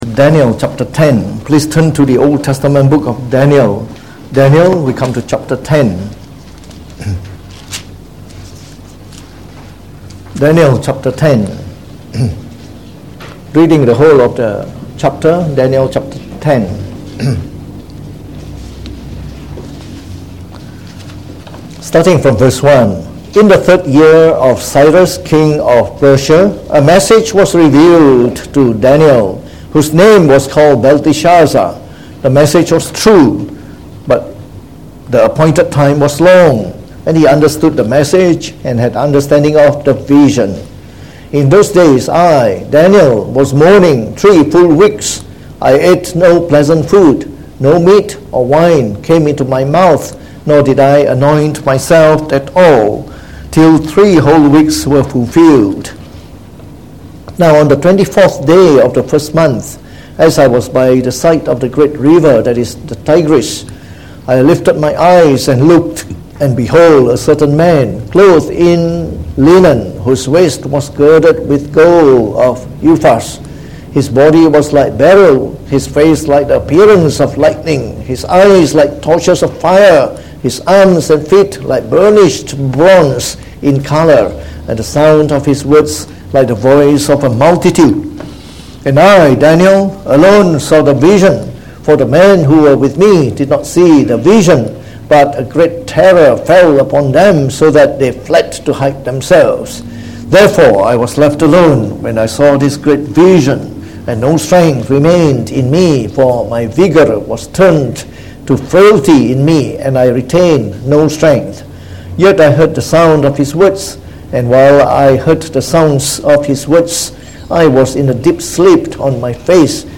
Preached on the 27th of January 2019.